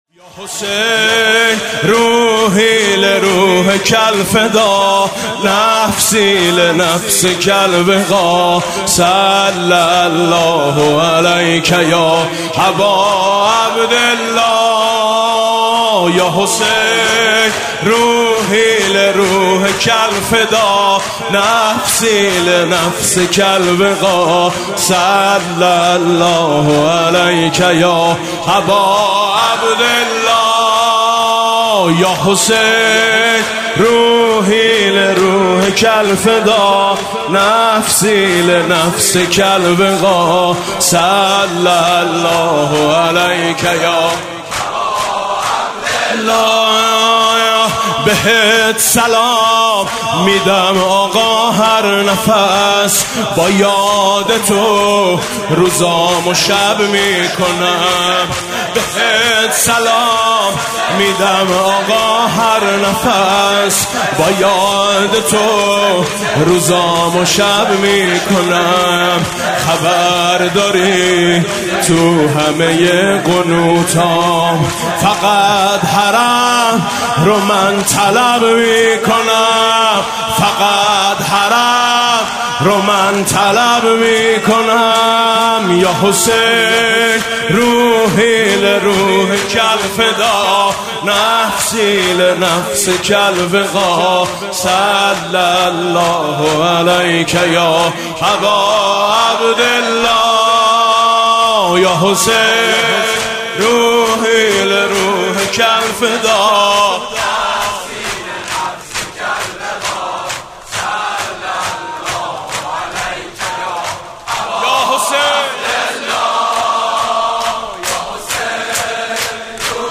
شور -یا حسین، روحی لِروحکَ الفداء